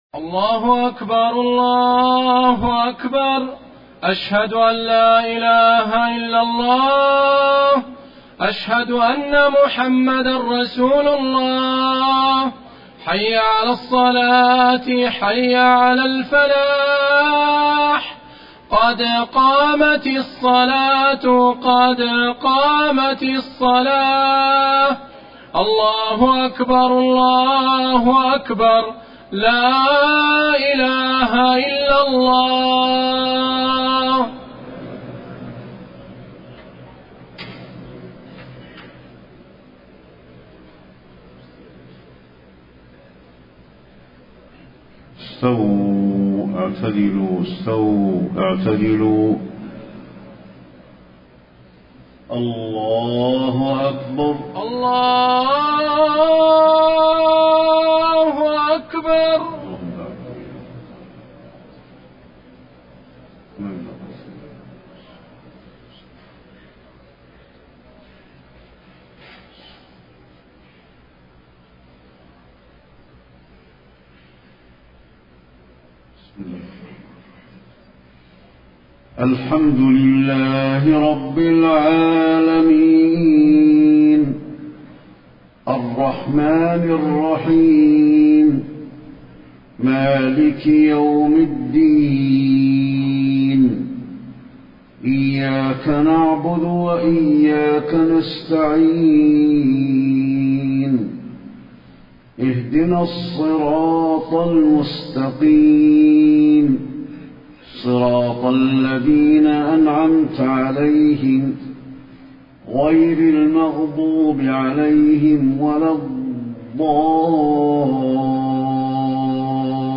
صلاة العشاء 3-9-1434 من سورة الشورى > 1434 🕌 > الفروض - تلاوات الحرمين